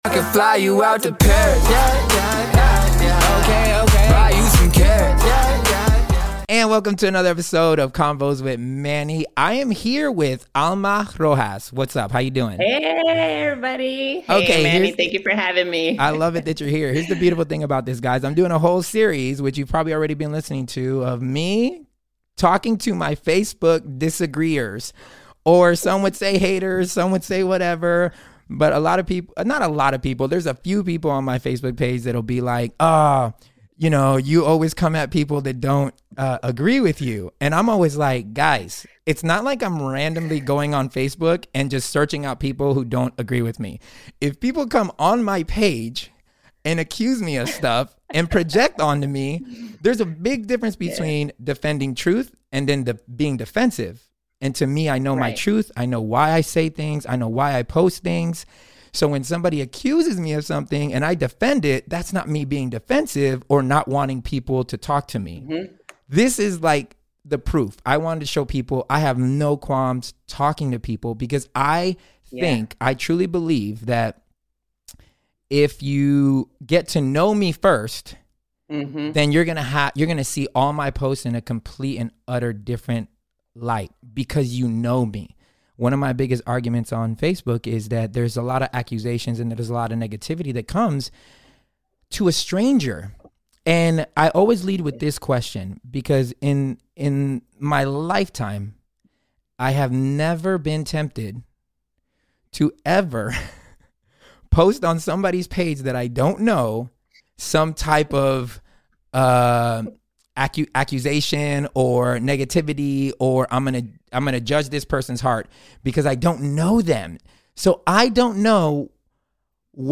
Don't let the title fool you, theres no arguing or mud slinging lol, just a friendly convo with some "haters" of mine on Facebook that turned out to be pretty amazing people. In this series I gave a chance to all the naysayers on my Facebook page, to join me on the podcast to ...